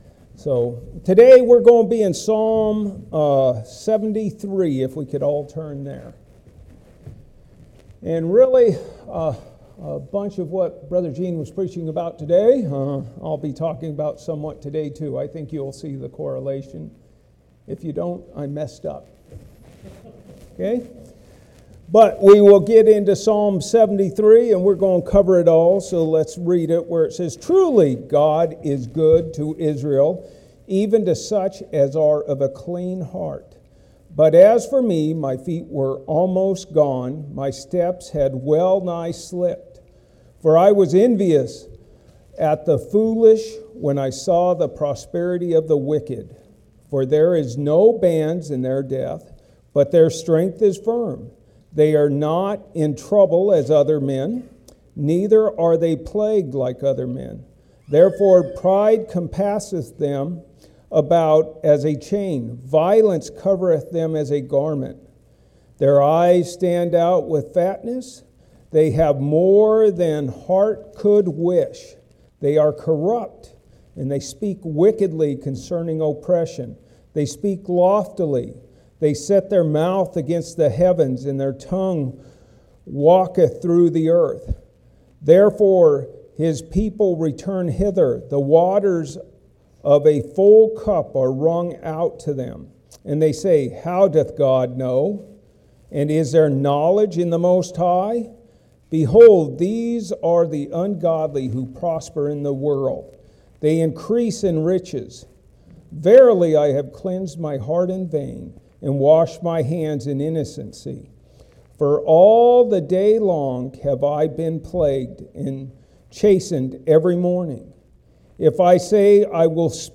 Passage: Psalm 73 Service Type: Sunday Afternoon